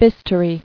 [bis·tou·ry]